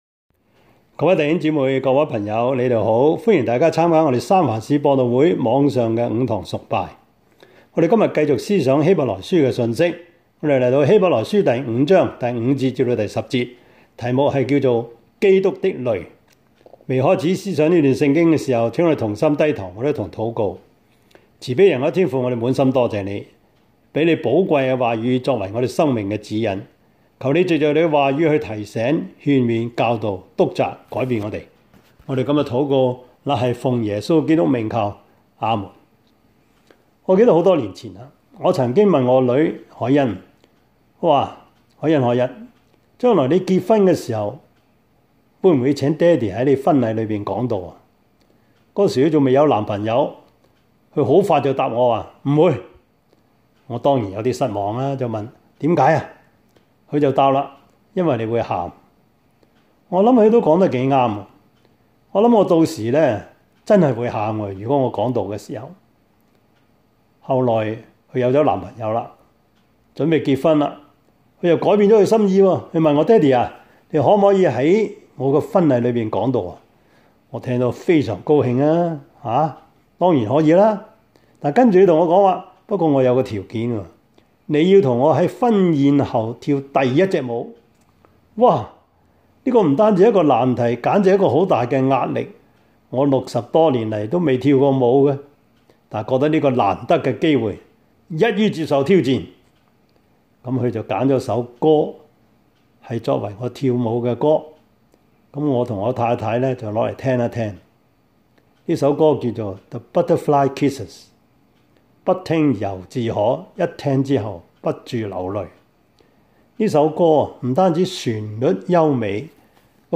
Series: 2021 主日崇拜 |